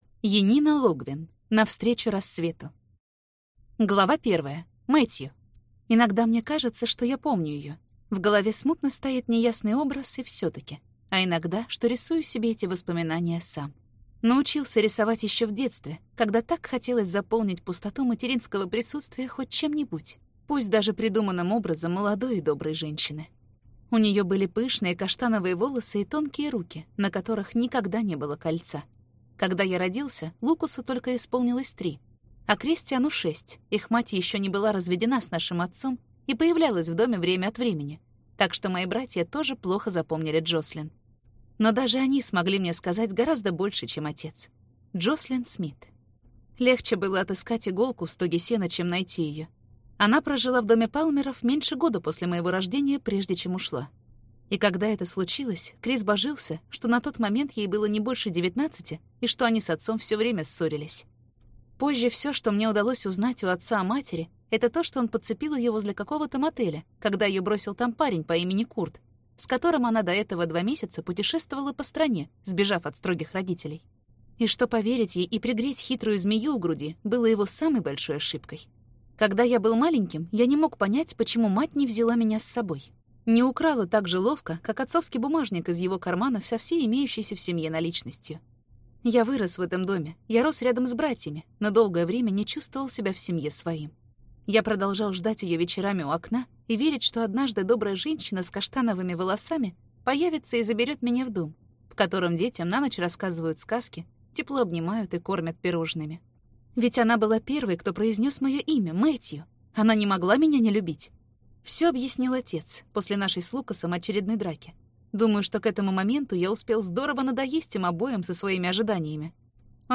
Аудиокнига Навстречу рассвету | Библиотека аудиокниг